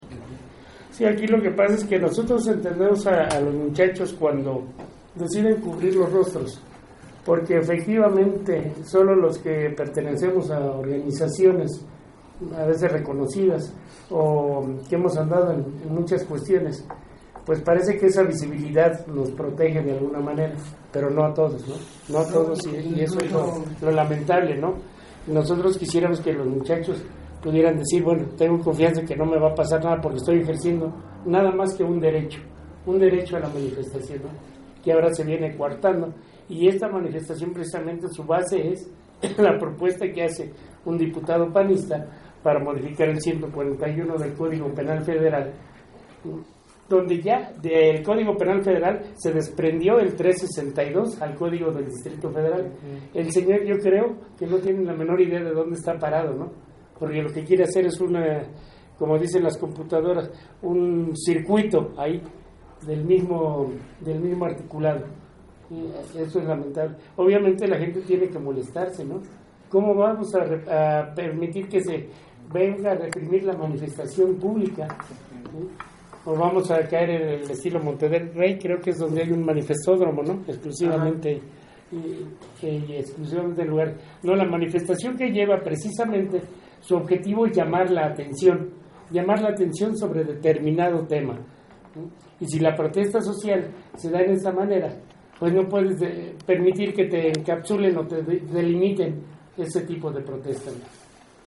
Uno de los integrantes de la Liga Mexicana por la Defensa de los Derechos Humanos (LIMEDDH) también fue parte de la comisión que ingresó, y explicó el porqué de la manifestación y algunos de los derechos Humanos están siendo violatorios.